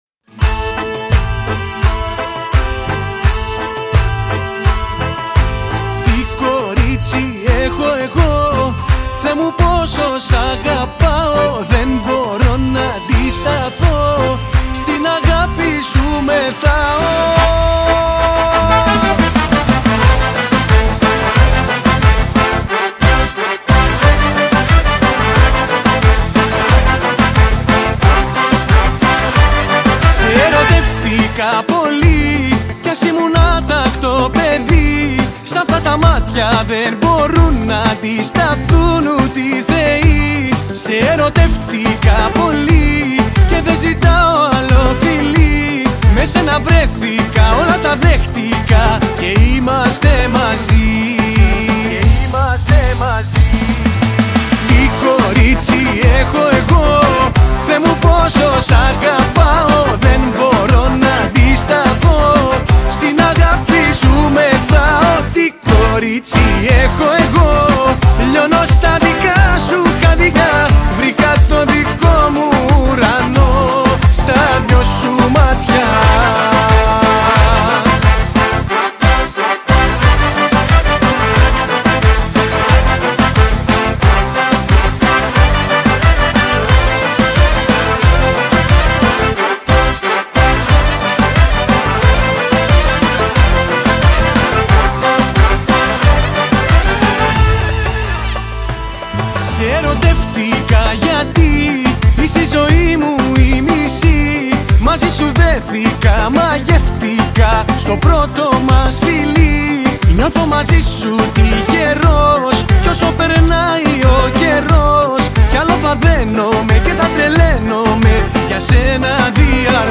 ΠΛΉΚΤΡΑ
ΚΛΑΡΊΝΟ